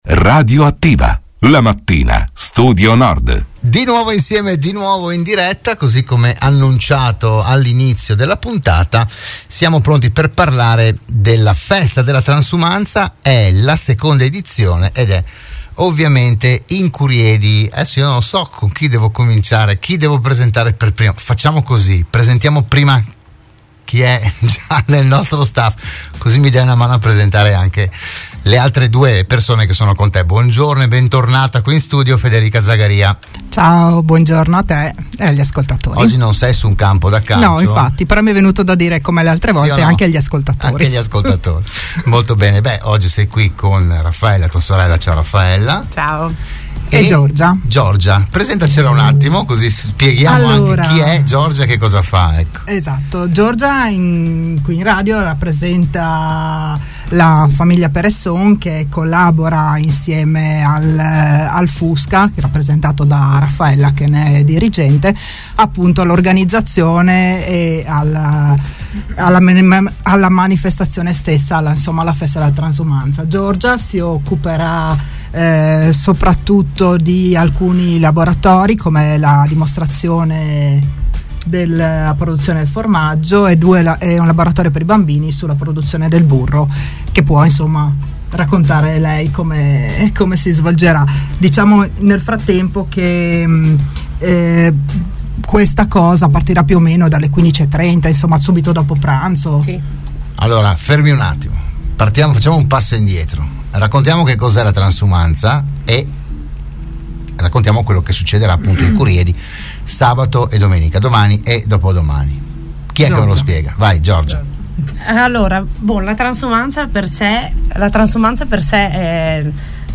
Della manifestazione si è parlato a “RadioAttiva“, la trasmissione di Radio Studio Nord